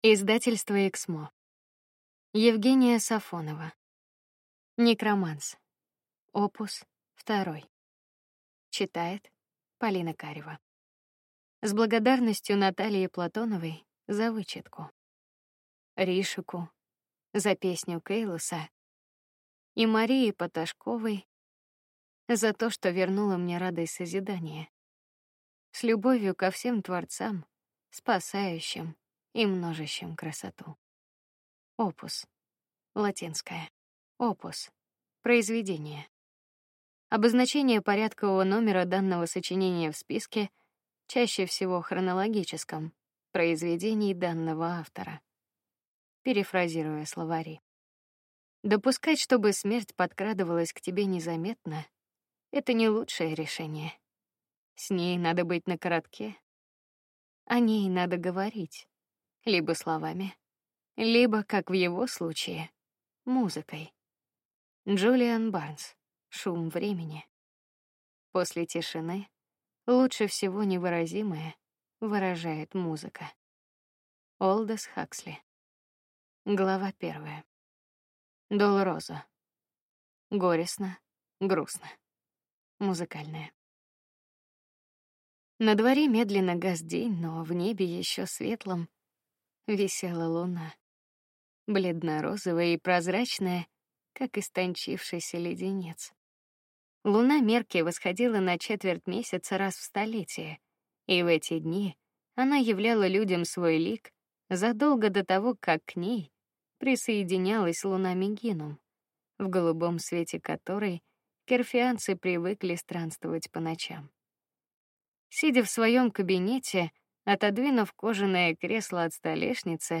Аудиокнига Некроманс. Opus 2 | Библиотека аудиокниг